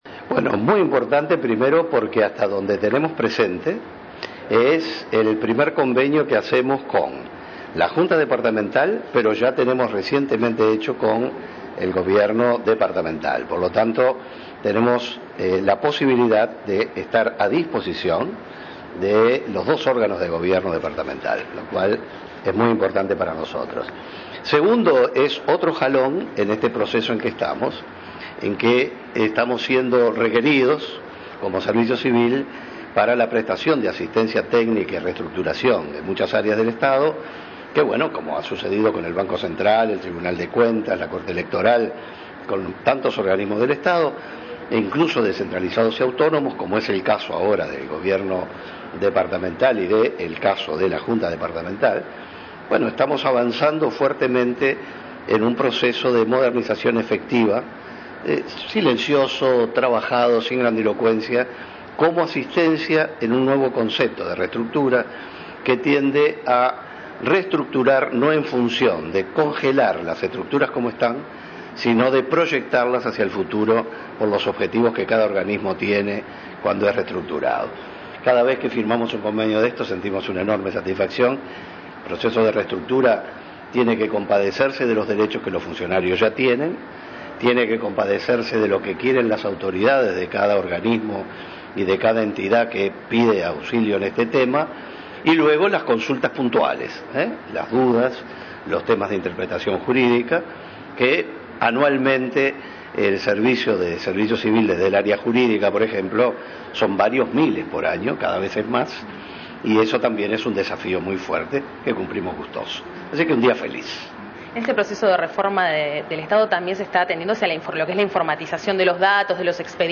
La Oficina Nacional del Servicio Civil firmó un acuerdo de cooperación con la Junta Departamental de Paysandú, a fin de brindar asistencia técnica para su reestructuración. “Estamos avanzando en un proceso de modernización efectivo, silencioso y trabajado”, sostuvo su director, Alberto Scavarelli, al término del acuerdo.